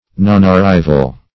Nonarrival \Non`ar*riv"al\, n. Failure to arrive.